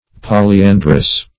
Search Result for " polyandrous" : Wordnet 3.0 ADJECTIVE (1) 1. having more than one husband at a time ; The Collaborative International Dictionary of English v.0.48: Polyandrous \Pol`y*an"drous\, a. (Bot.)